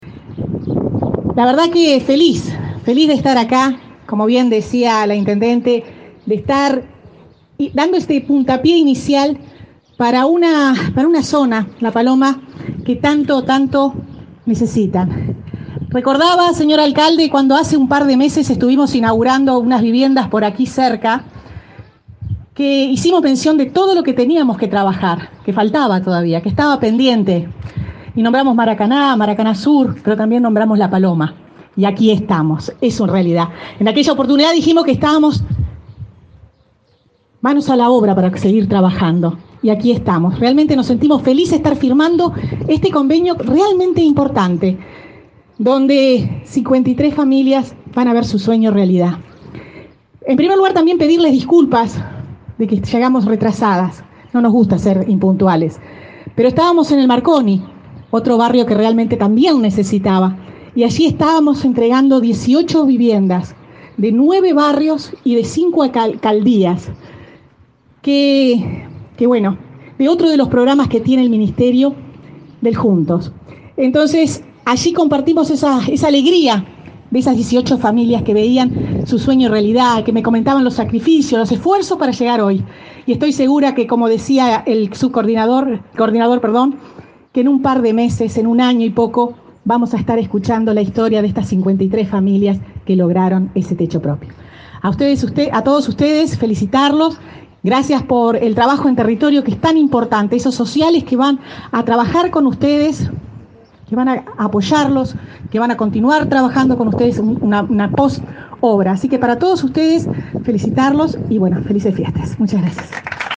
Palabras de la ministra de Vivienda, Irene Moreira